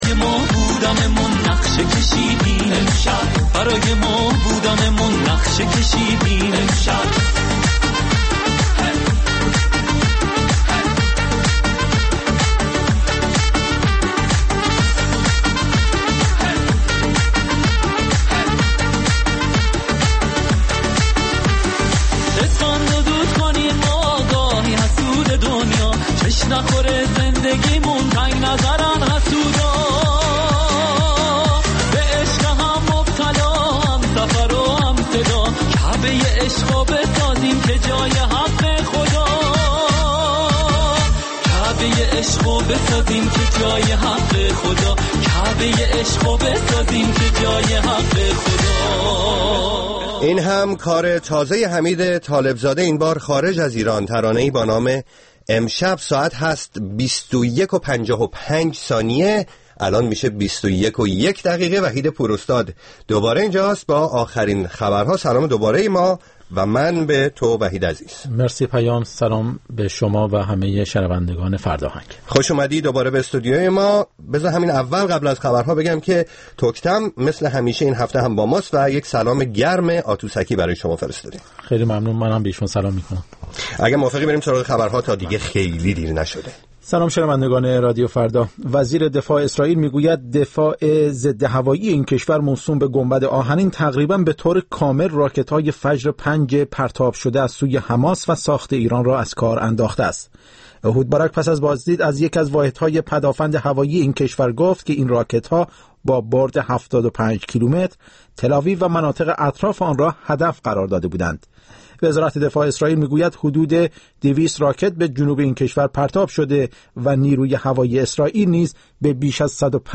پنجشنبه‌ها از ساعت هشت شب به مدت دو ساعت با برنامه زنده موسیقی رادیو فردا همرا ه باشید.